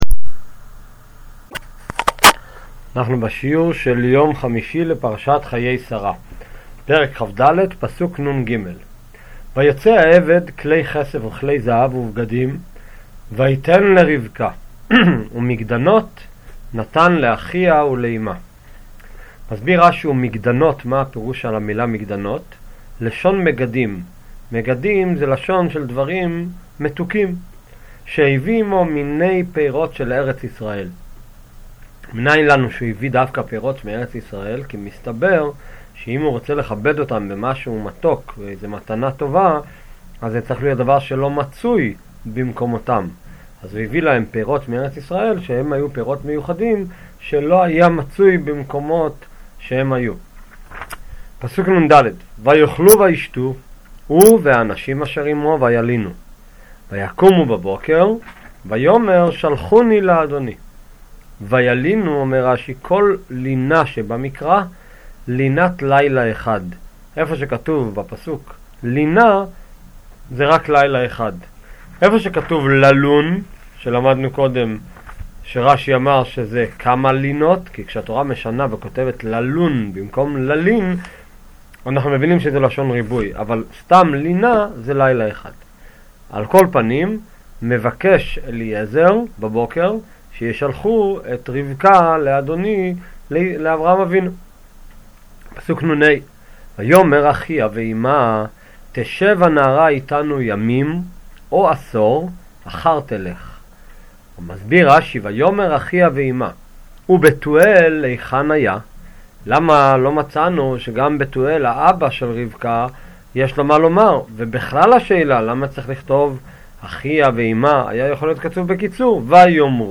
שיעורי תניא | חומש עם רש״י | שיעורי תורה